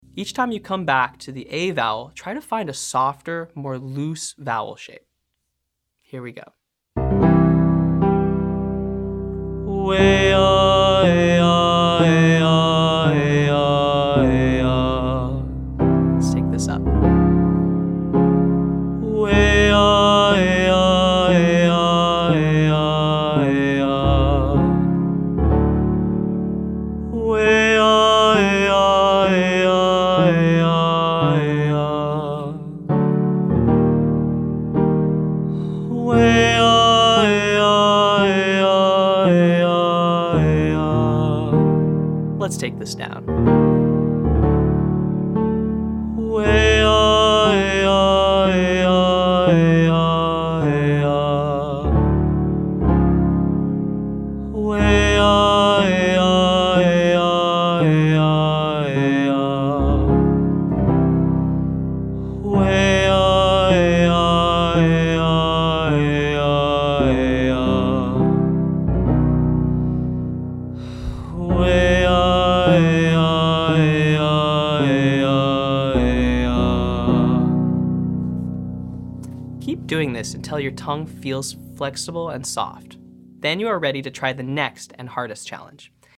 Day 4: Vocal Tension Release - Online Singing Lesson